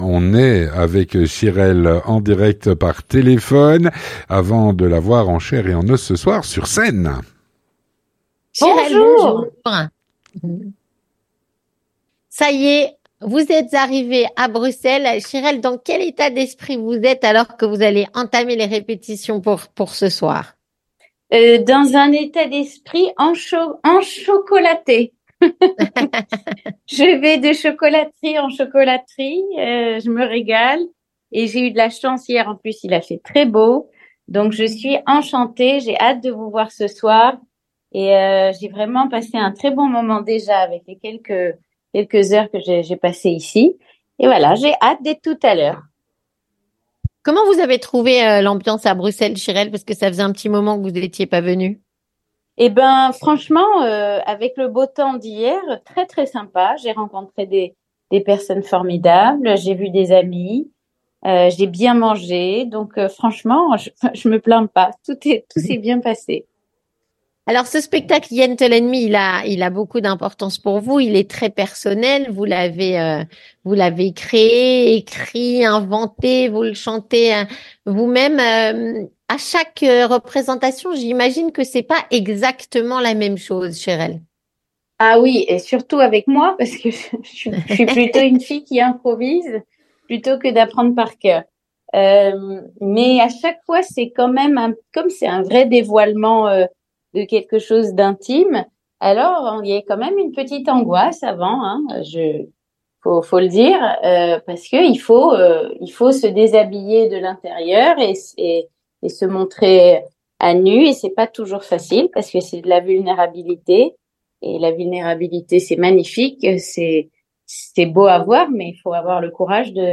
Avec Shirel, artiste, chanteuse, auteure et interprète.